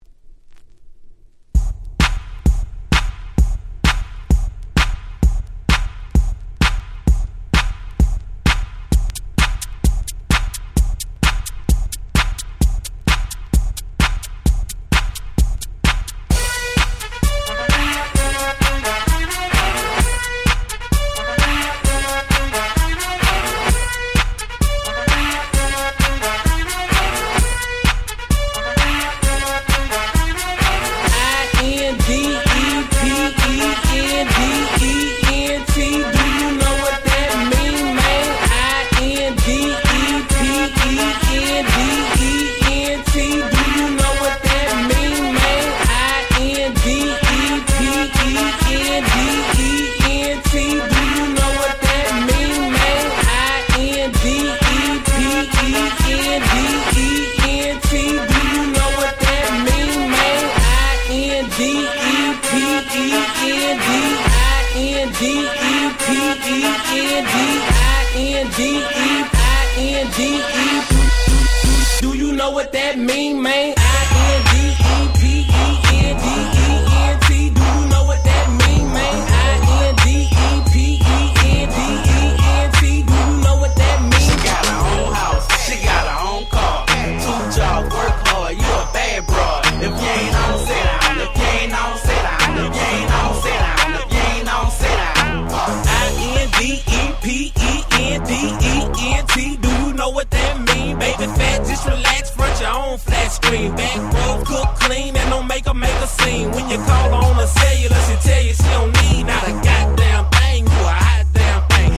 DJがPlay中にBPMを変えるのに超便利なトランジション物を全6曲収録しためちゃ使える1枚！！
(130BPM-87BPM)